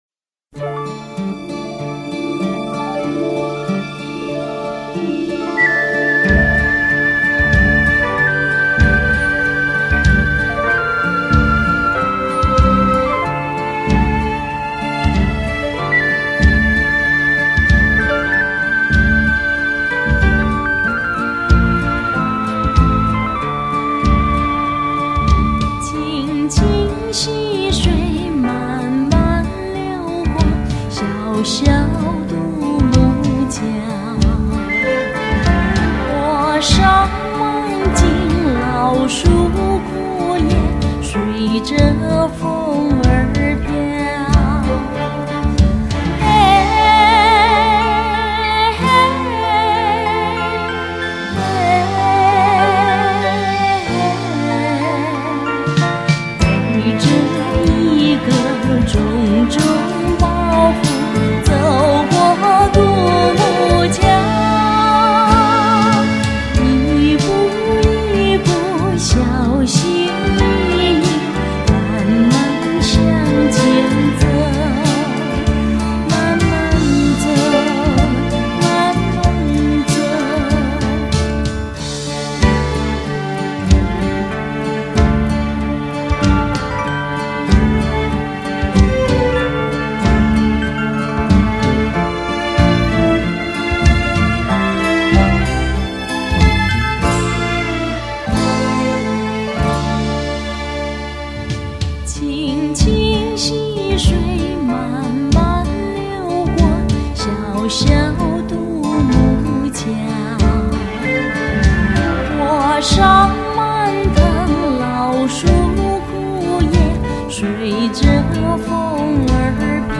輕快活潑的歌聲，多年後依然縈繞不休！